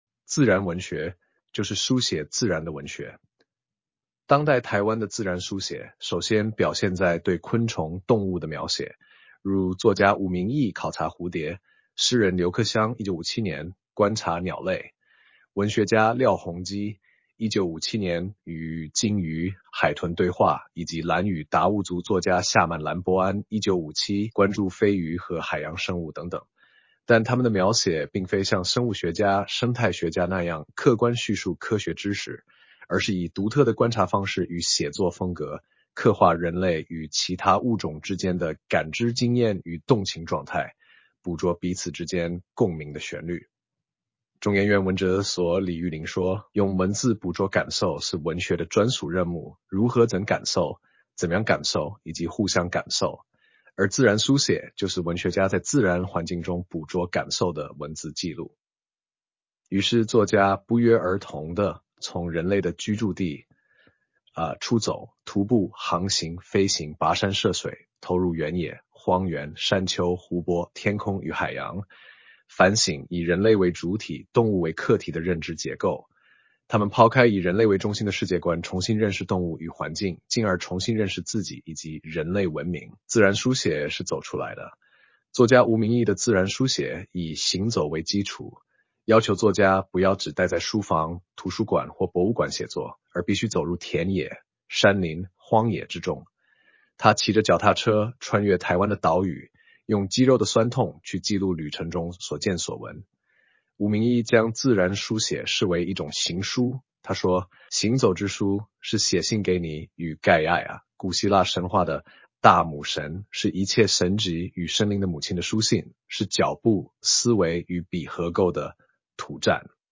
全文朗讀：